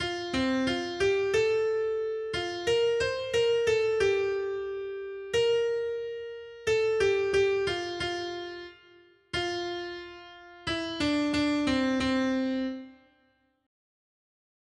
Noty Štítky, zpěvníky ol653.pdf responsoriální žalm Žaltář (Olejník) 653 Skrýt akordy R: Pane, ukaž nám své milosrdenství! 1.